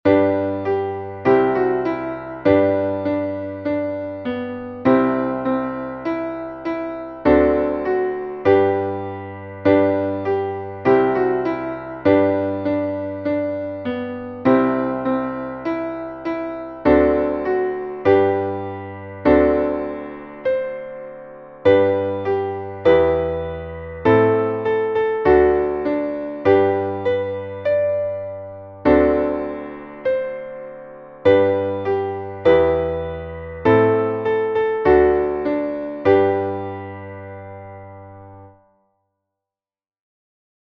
Traditionelles Volkslied (auch Anti-Kriegslied)